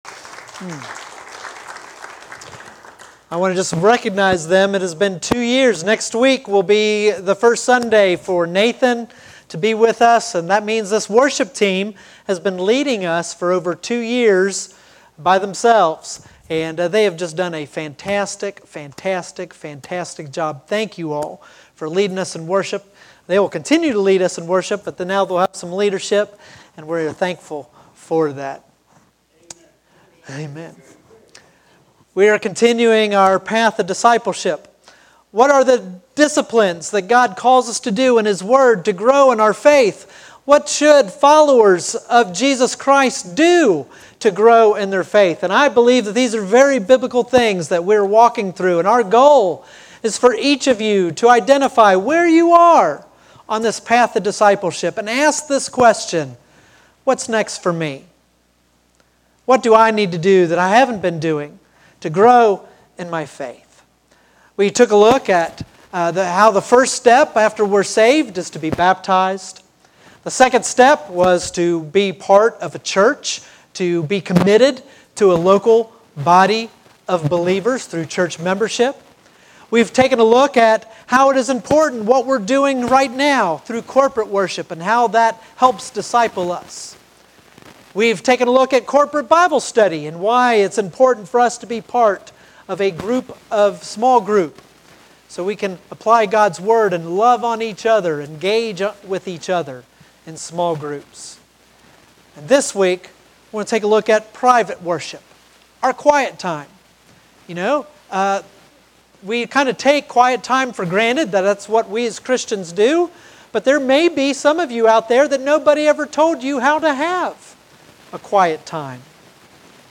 Sermons | Flint Hill Baptist Church